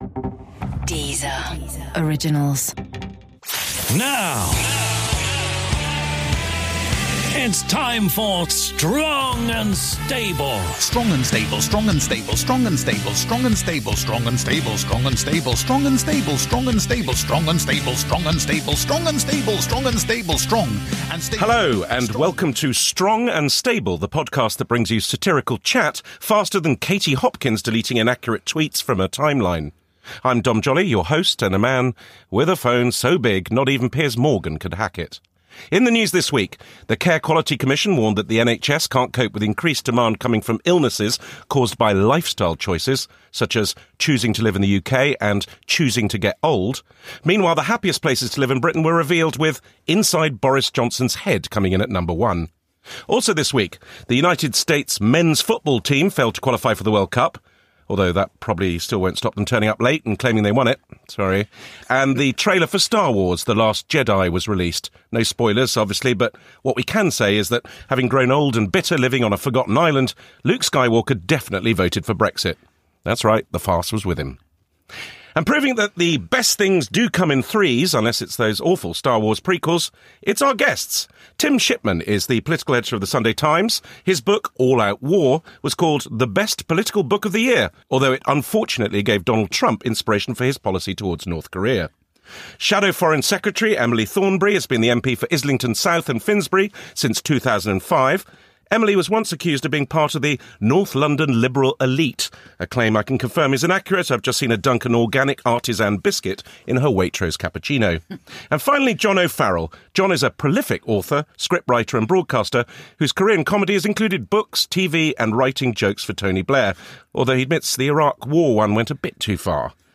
This week on Strong & Stable our host Dom Joly is joined in the studio by guests Tim Shipman, Emily Thornberry and John O’Farrell. On the agenda are Trump, the threat of a 'no-deal' Brexit and the Tory trouble with the youth vote. We also hear from roving reporter Jonathan Pie.